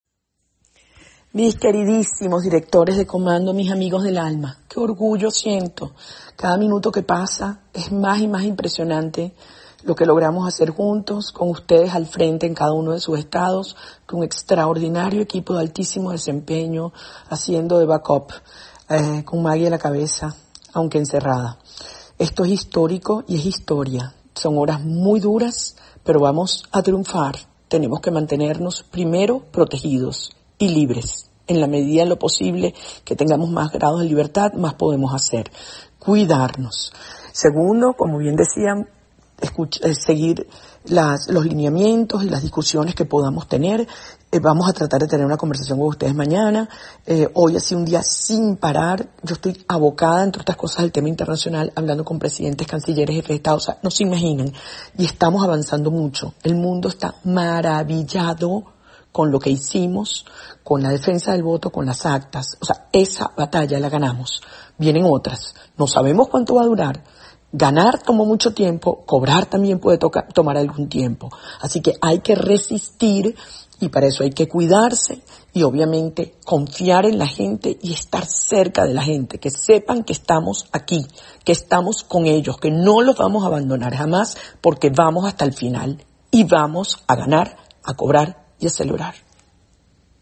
En un audio enviado a nuestra Redacción por uno de sus colaboradores, Machado les agradece el intenso trabajo en esta campaña y les instruye a cuidarse antes que todo: "Son horas muy duras, pero vamos a triunfar, tenemos que mantenernos primero protegidos y libres en la medida de lo posible".